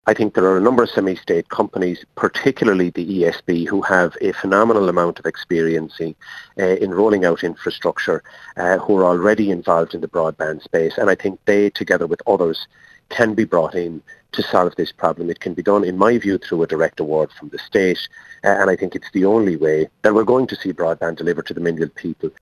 Fianna Fail’s communications spokesperson, Timmy Dooley, says a semi-state company may be the only hope of delivering the project: